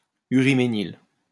Uriménil (French pronunciation: [yʁimenil]